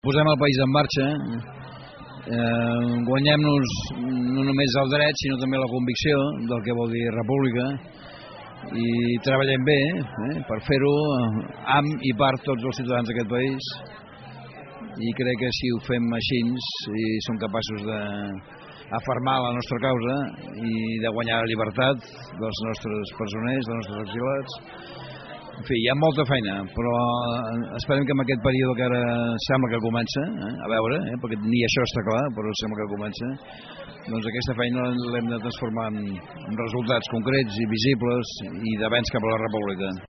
Són declaracions de Maragall a Ona Malgrat.